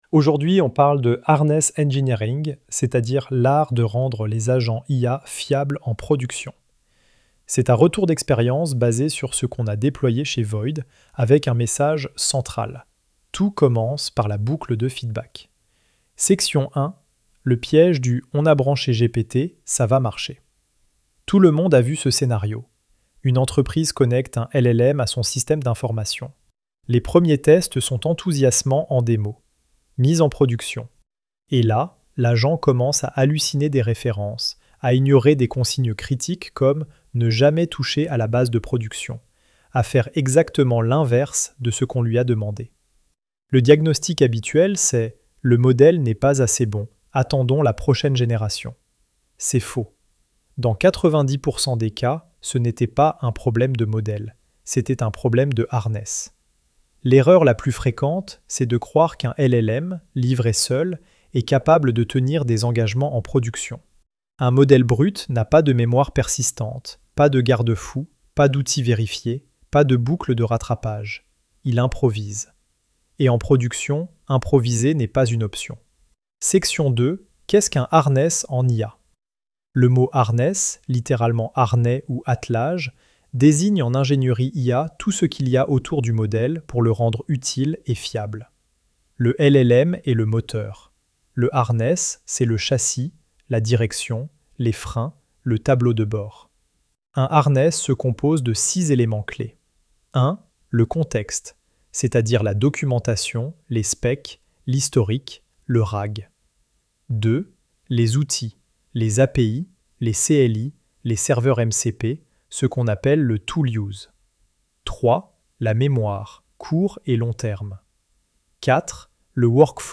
Écouter l'article — narré par VOID